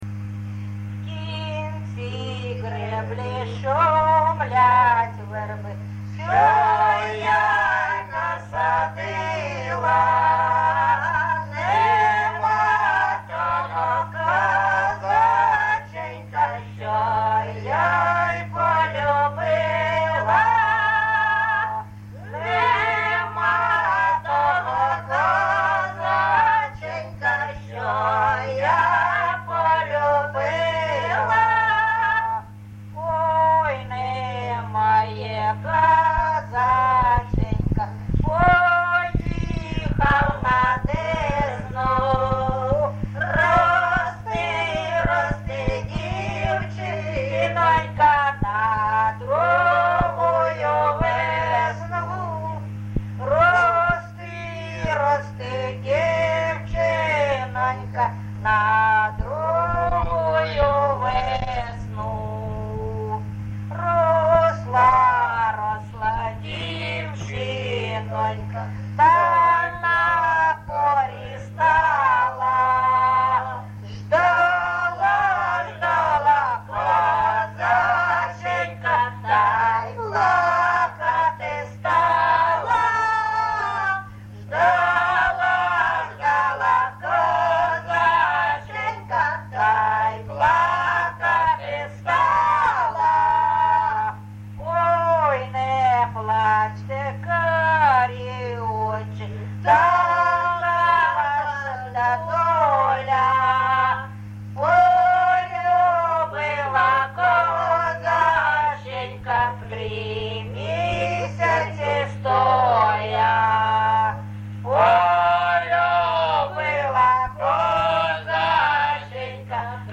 ЖанрПісні з особистого та родинного життя
Місце записус. Бузова Пасківка, Полтавський район, Полтавська обл., Україна, Полтавщина